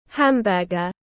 Bánh ham-bơ-gơ tiếng anh gọi là hamburger, phiên âm tiếng anh đọc là /ˈhæmˌbɜː.ɡər/
Hamburger /ˈhæmˌbɜː.ɡər/